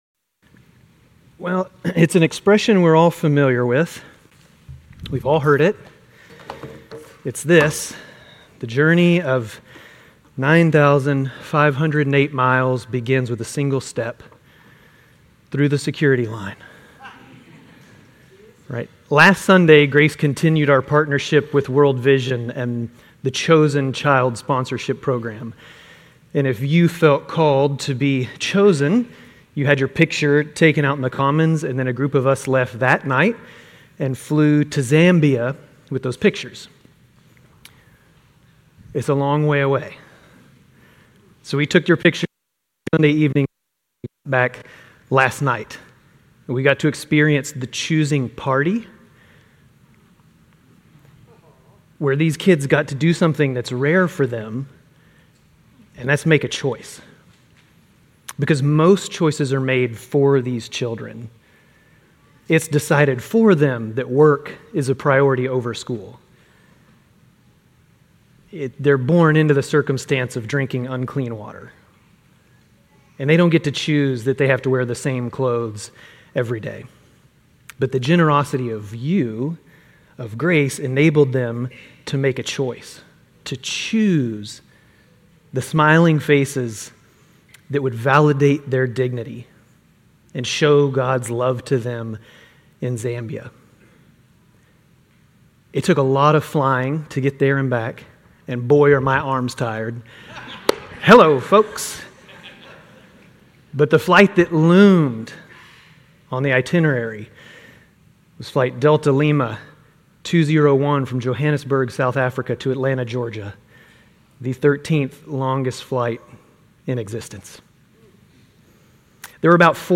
Grace Community Church Lindale Campus Sermons Galatians 3:1-14 Salvation by Faith not Works Apr 29 2024 | 00:23:06 1x 00:00 / 00:23:06 Subscribe Share RSS Feed Share Link Embed